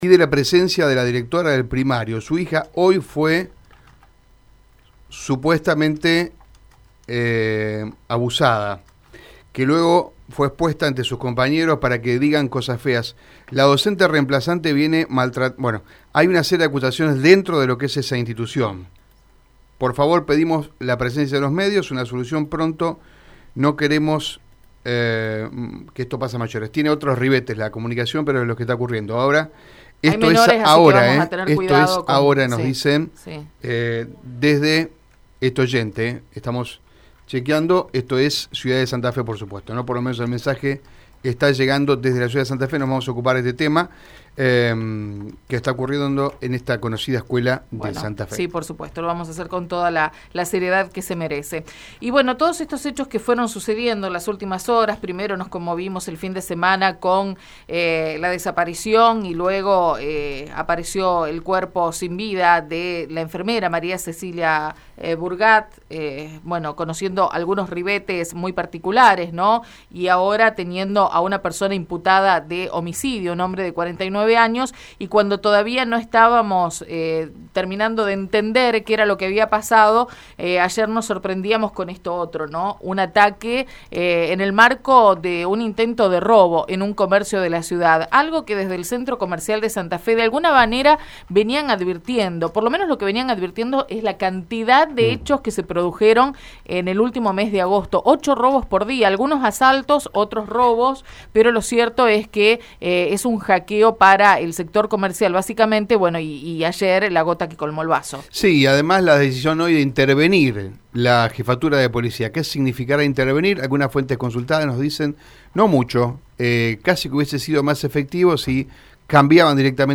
El ex juez santafesino habló en Radio EME e hizo referencia a los altos niveles de inseguridad en la ciudad de Santa Fe.